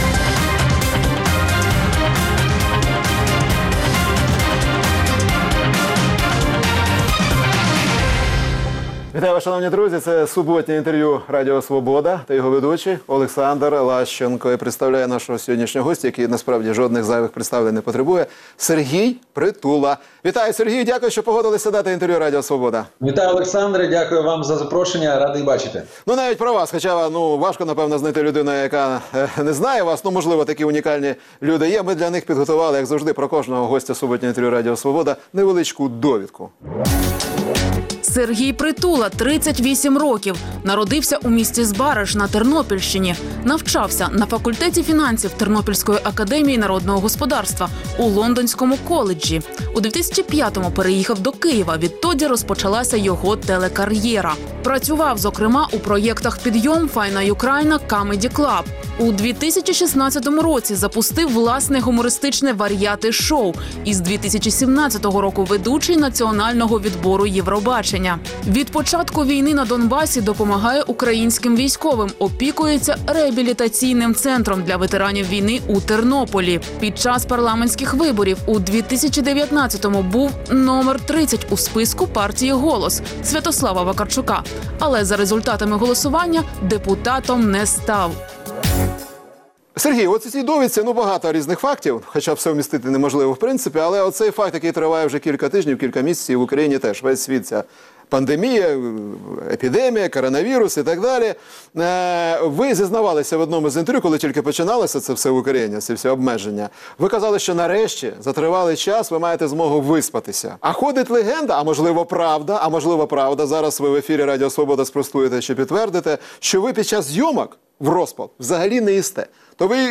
Суботнє інтерв’ю | Сергій Притула, шоумен
Суботнє інтвер’ю - розмова про актуальні проблеми тижня. Гість відповідає, в першу чергу, на запитання друзів Радіо Свобода у Фейсбуці